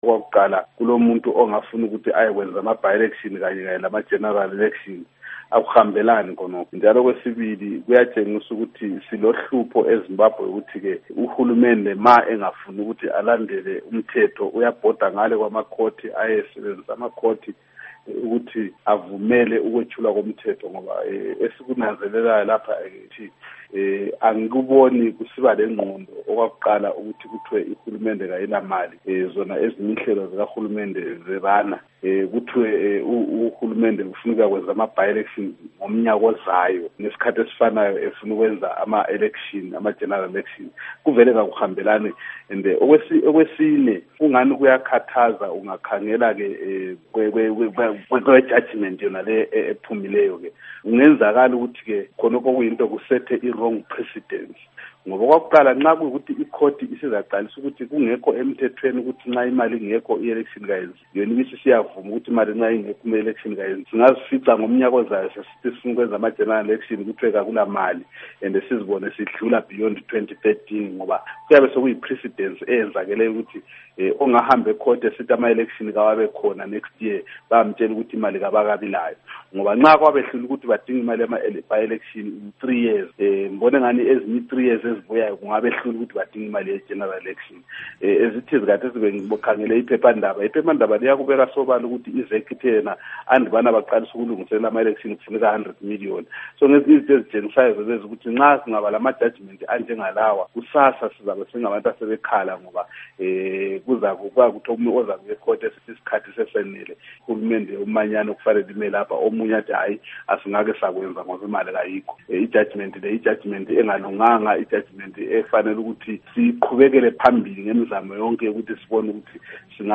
Ingxoxo LoMnu. Abednico Bhebhe